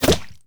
bullet_impact_mud_05.wav